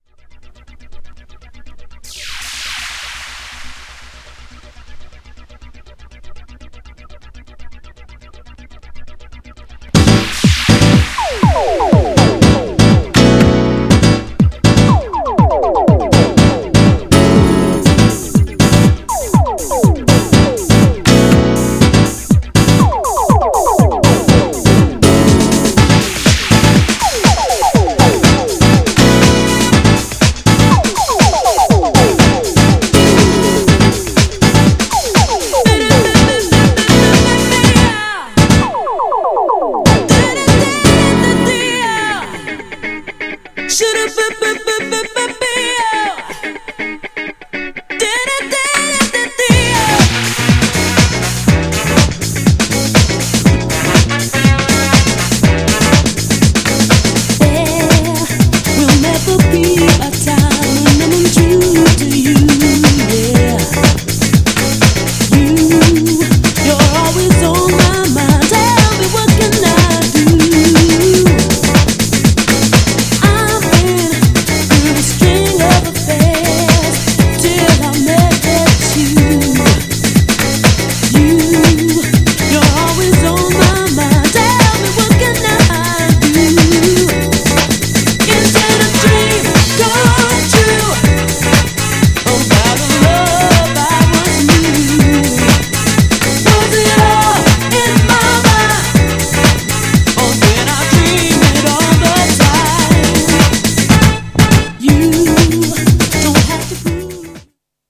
GENRE R&B
BPM 121〜125BPM